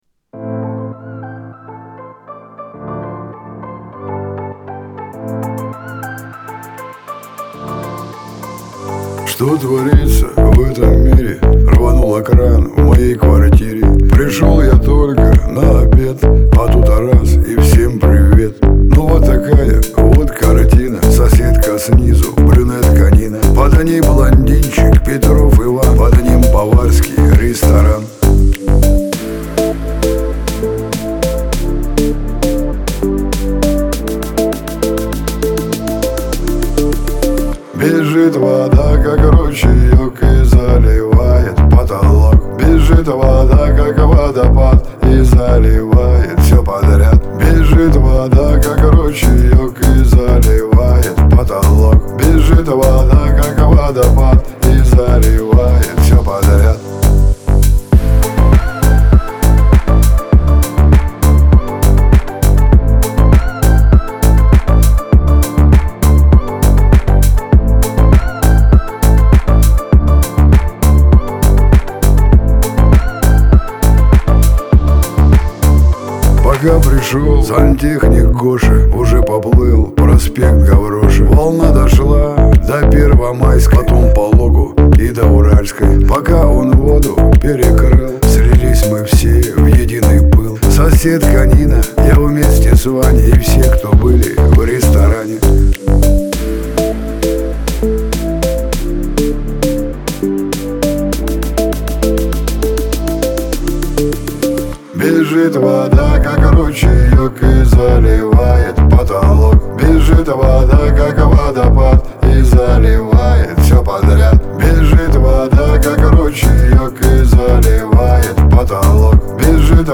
Шансон , Лирика , грусть